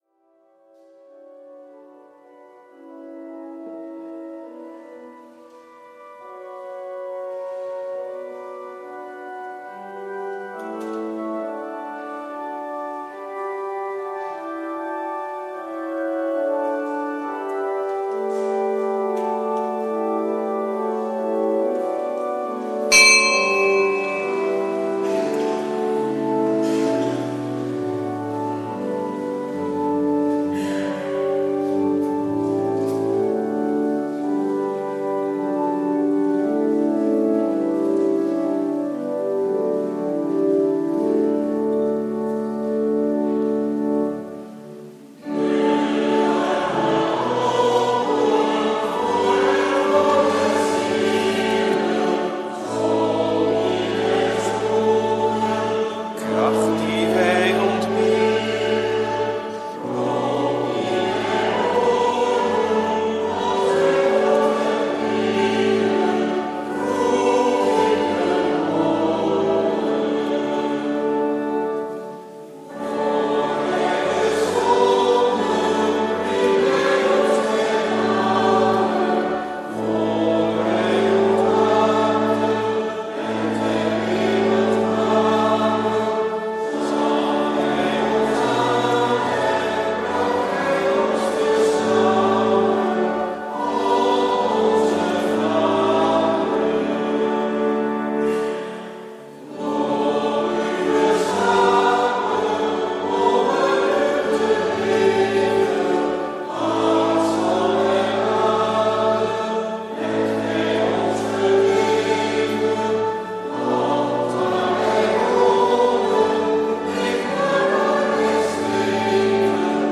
Preek 15e zondag, door het jaar B, 11/12 juli 2015.
Eucharistieviering beluisteren vanuit de H. Joannes de Doper te Katwijk (MP3)